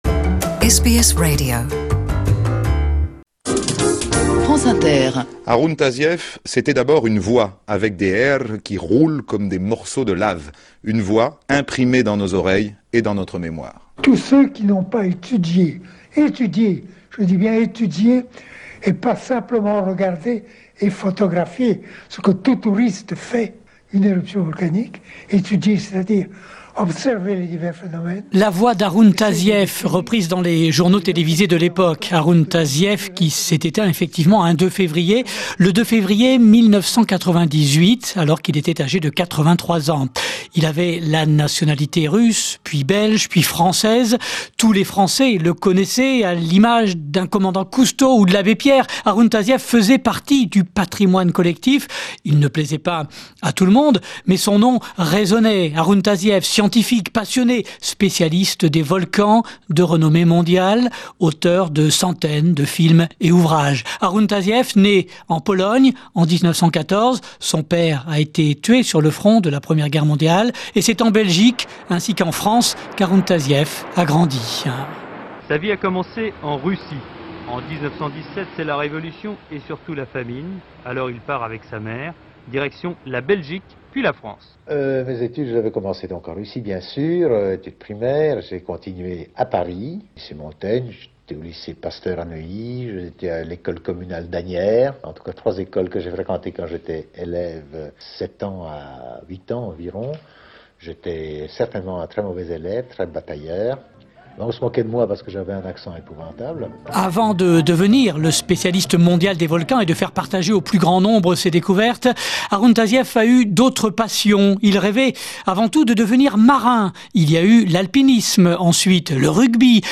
Rubrique préparée avec les archives sonore de l'INA.